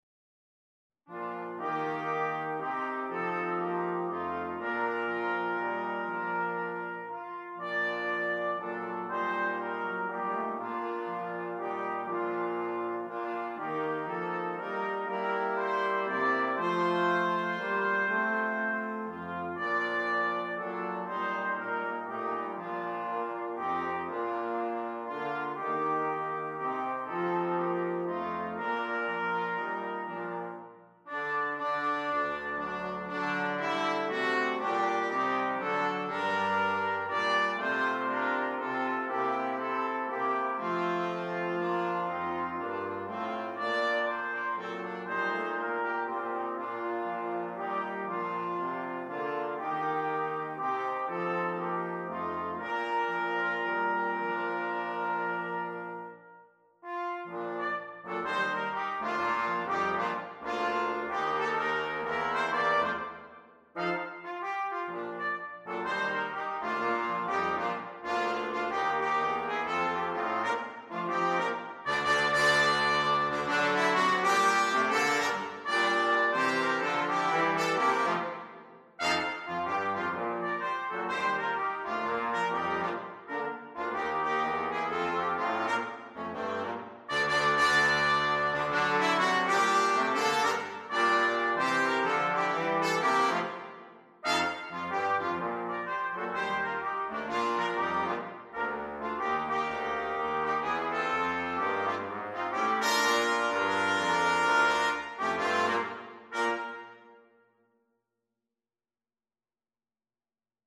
Jazz and Blues